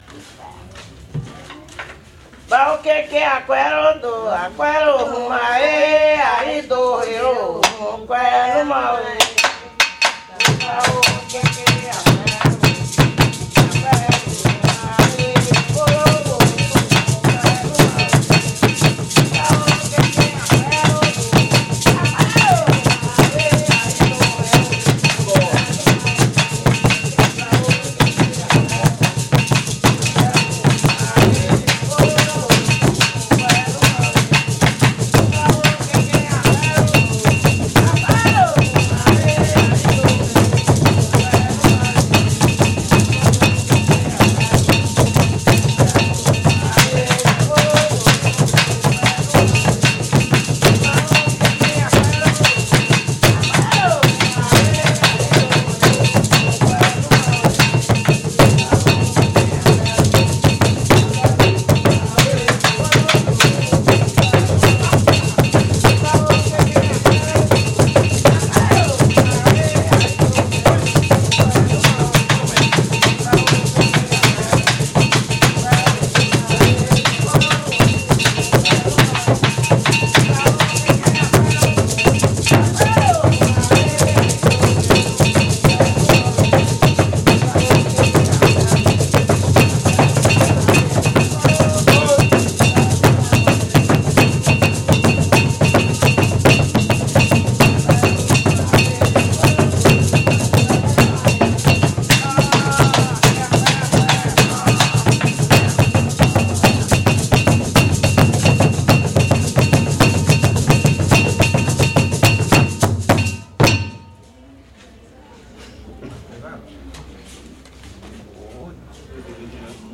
A comunidade da Casa das Minas é composta em sua maioria por mulheres, que detêm papéis e cargos importantes. Os homens têm atuação específica e limitada, com a função principal de tocar os tambores (eles não recebem voduns). É um grupo religioso discreto e muito tradicional que cultua divindades chamadas de voduns: entidades a quem se pede ajuda; que possuem defeitos e estão espalhados para administrar o universo; que estão hierarquicamente entre os homens e os santos e, assim, fazem o intermédio entre eles.